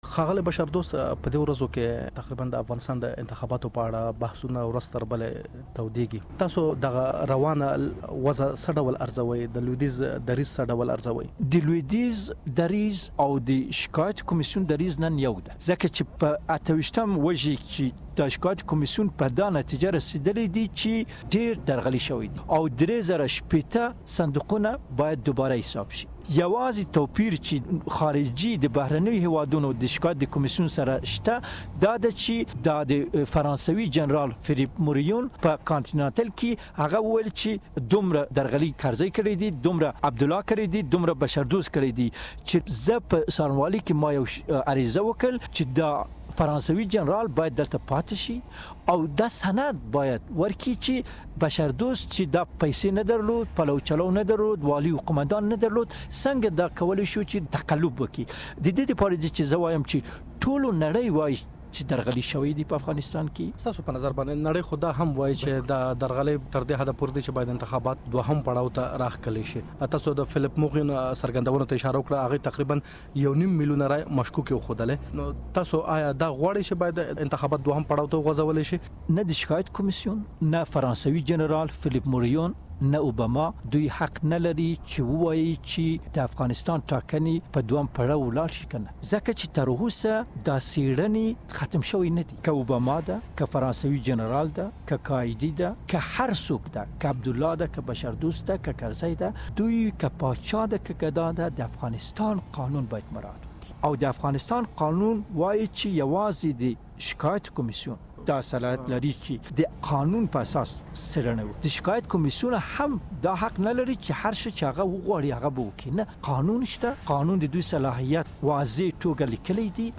له رمضان بشردوست سره مرکه واورﺉ